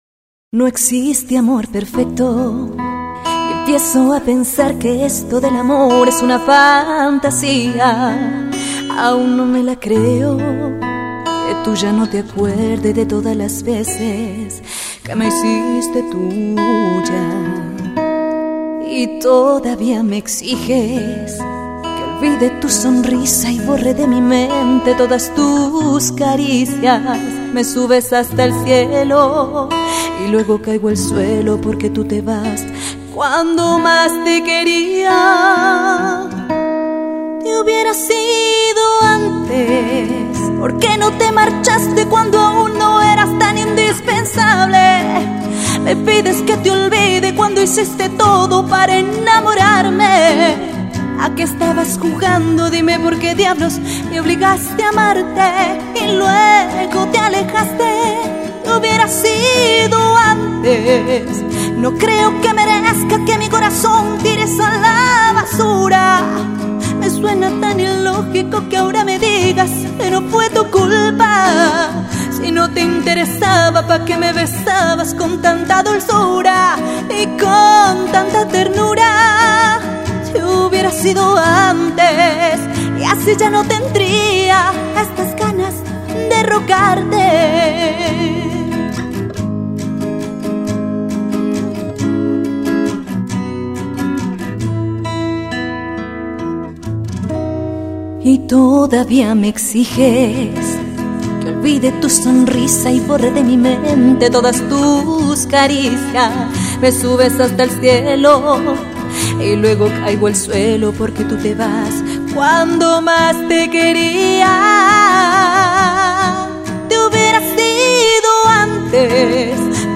de una manera acústica
guitarra